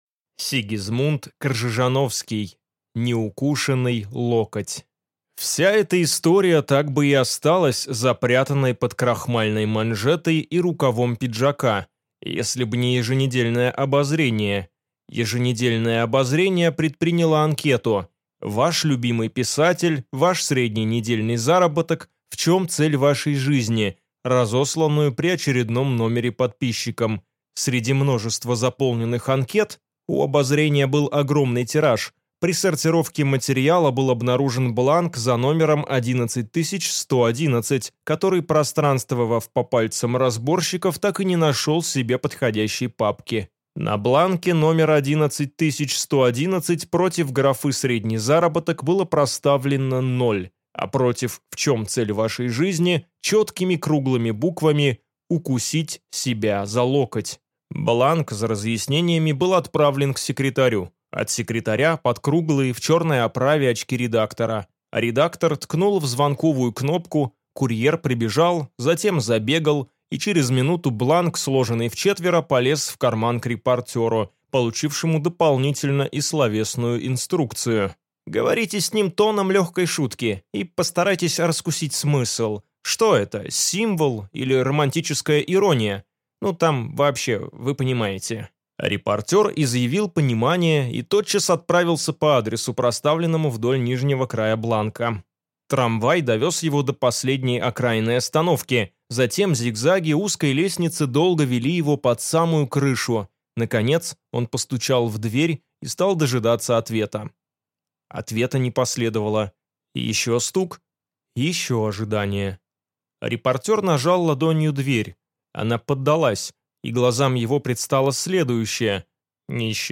Аудиокнига Неукушенный локоть | Библиотека аудиокниг